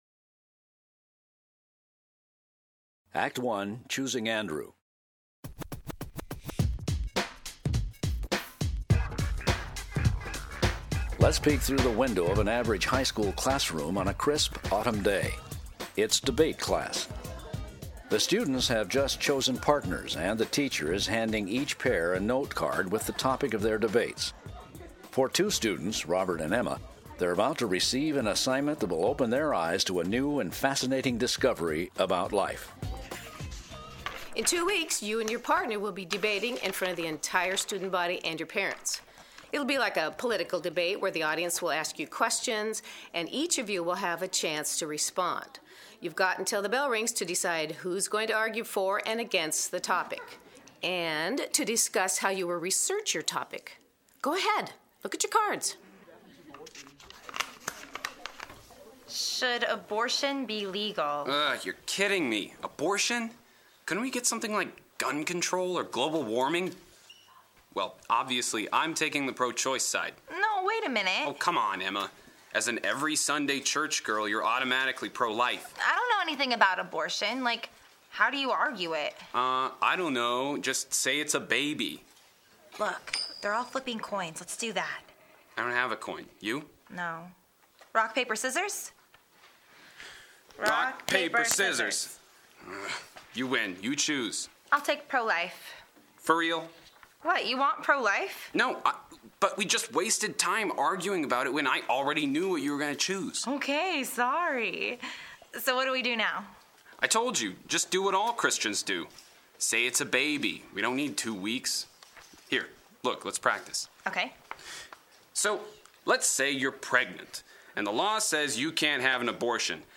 Robert & Emma Audio Play - Act 1
Robert and Emma is a four-act audio drama that follows two friends who discover how precious life is from its first beginning…to the very end. It’s a fun and engaging way for students to learn about the Ten Universal Principles, and hear them applied to real life situations.